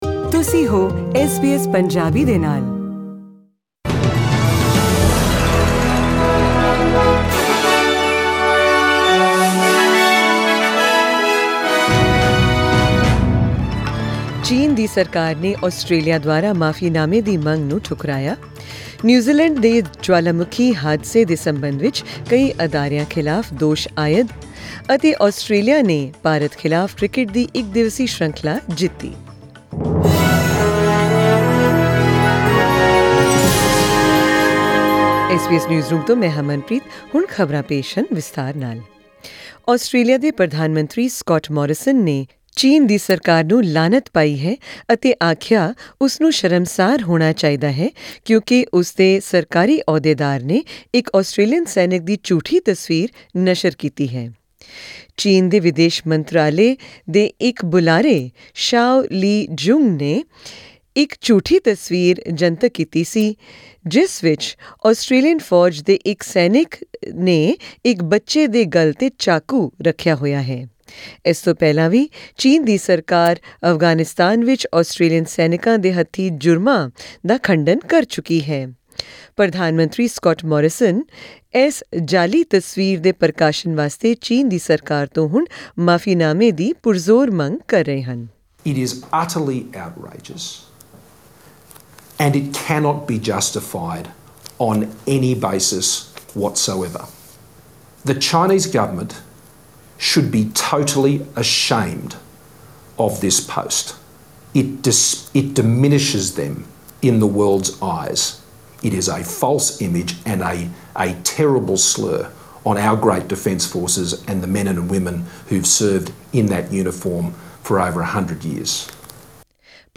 In this news bulletin, hear about the war of words between Australia and China, know more about Victoria's new hotel quarantine program starting on December 7, and Australia's win over India in the cricket OCI series.